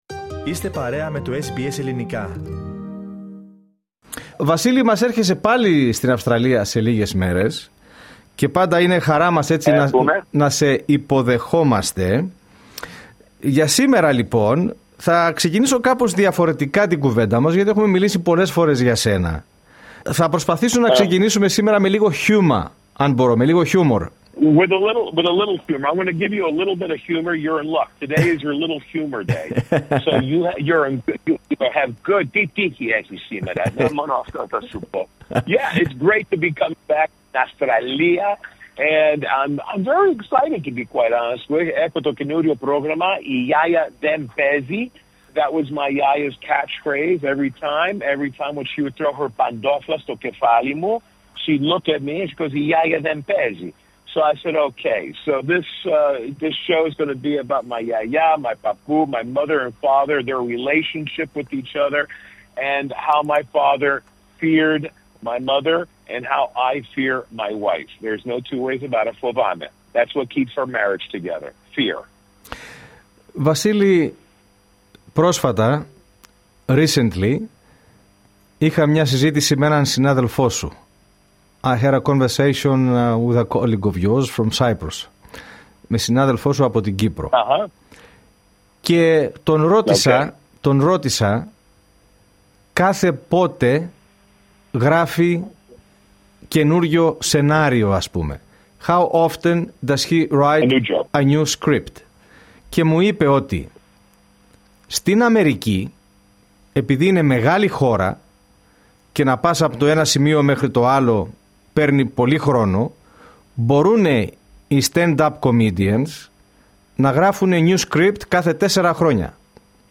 More abou the performances in this interview that he gave to SBS Greek.